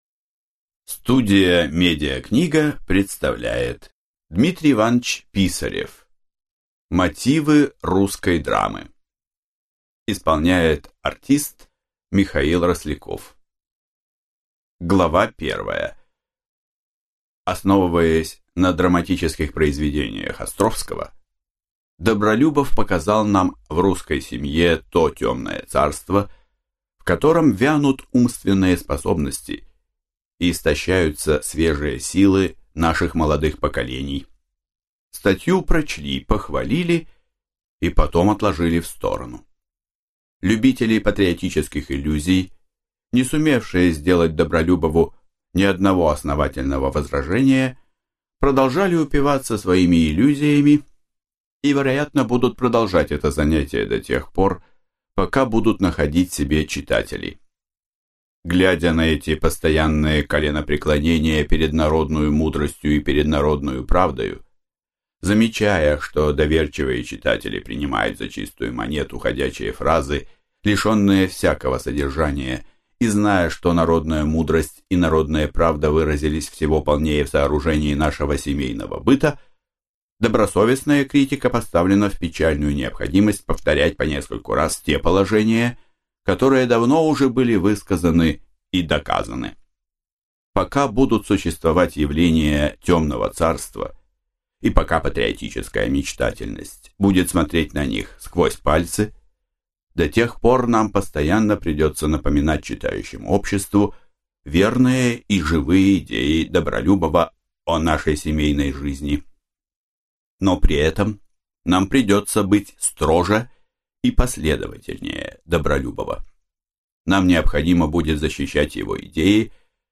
Аудиокнига Мотивы русской драмы | Библиотека аудиокниг